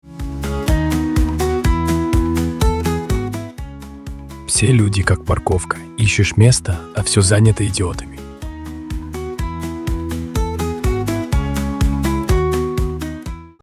🔊 Как звучит шутка >>>
Вся музыка сгенерирована ИИ и не только музыка - здесь виртуальные ведущие и голоса в рубриках новости, прогноз погоды, и тп